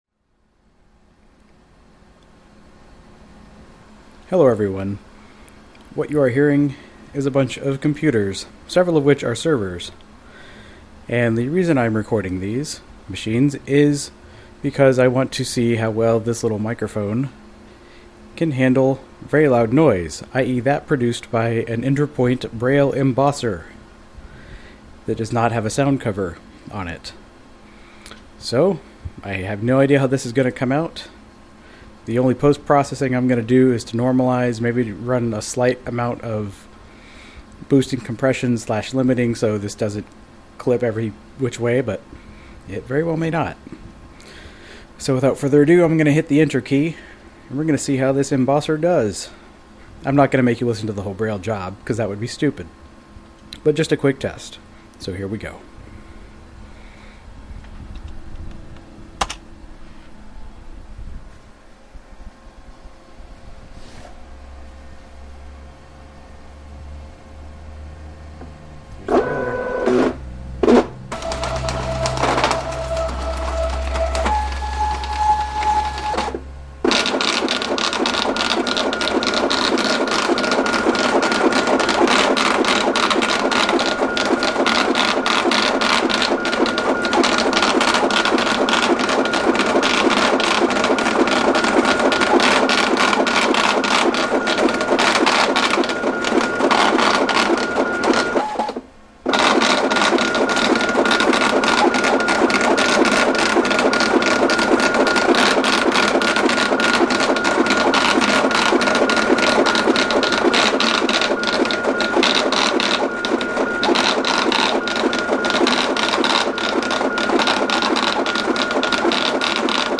Brailler Test
A quick test to see whether a loud interpoint Braille embosser can overmodulate the Rode iXY microphone.